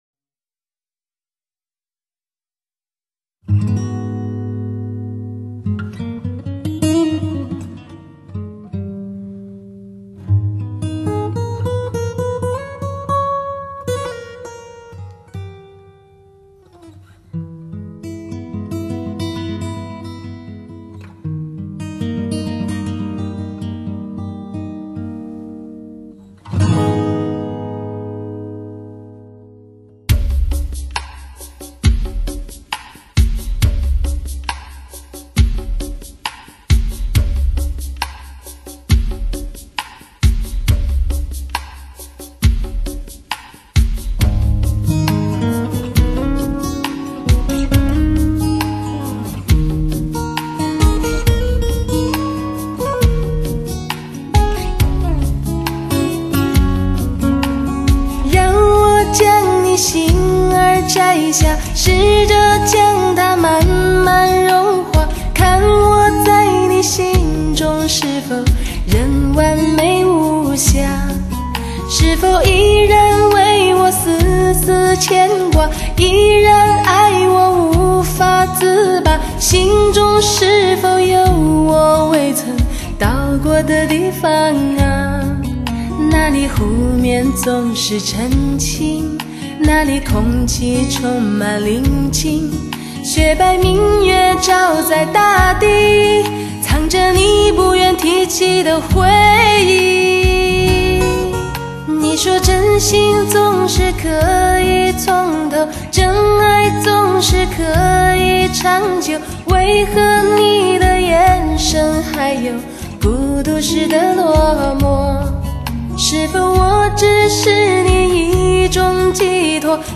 辽阔、多情的旋律，就像马奶酒一样让人如痴如醉。
最高取样率加特量化深度＝大动态、高临场感。